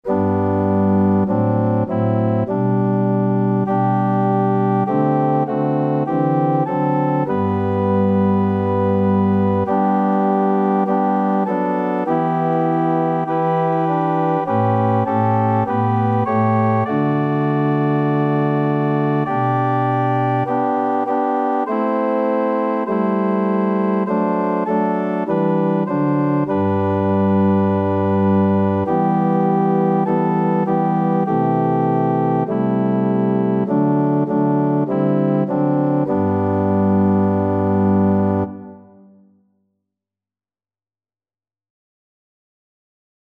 Free Sheet music for Organ
G major (Sounding Pitch) (View more G major Music for Organ )
2/2 (View more 2/2 Music)
Organ  (View more Intermediate Organ Music)
Classical (View more Classical Organ Music)